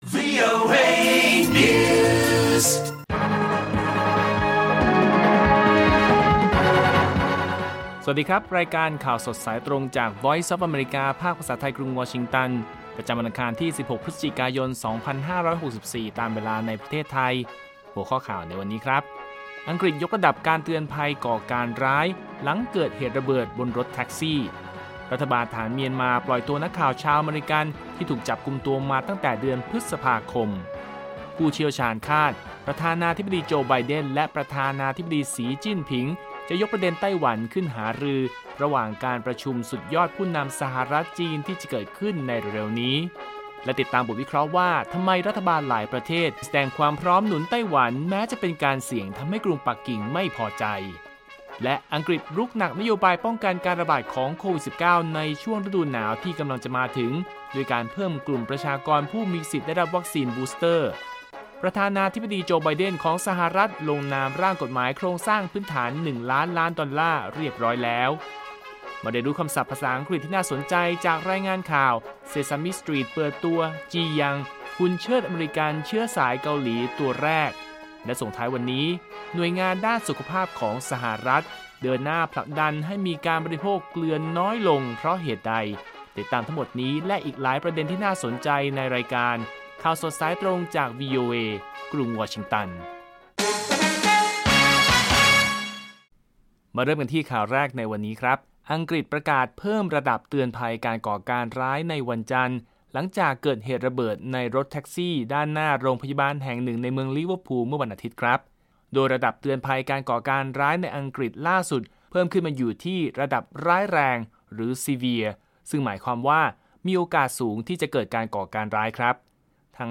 ข่าวสดสายตรงจากวีโอเอ ภาคภาษาไทย ประจำวันอังคารที่ 16 พฤศจิกายน 2564 ตามเวลาประเทศไทย